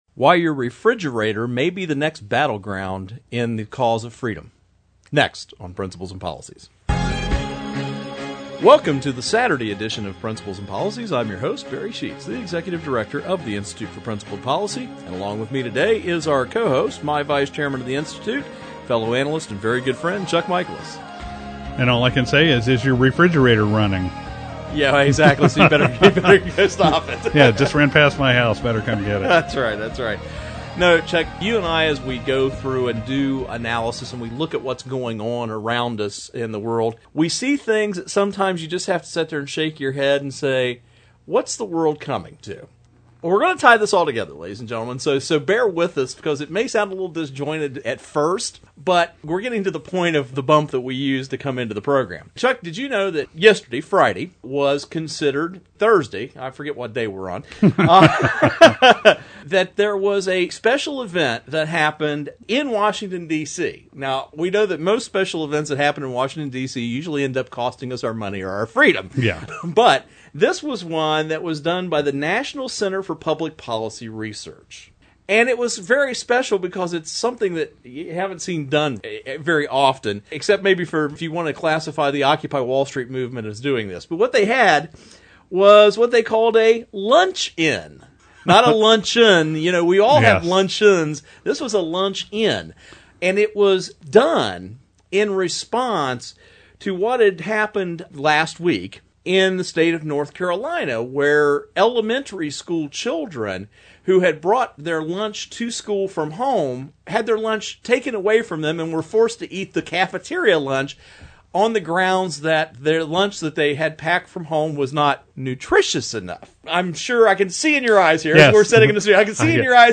Our Principles and Policies radio show for Saturday February 25, 2012.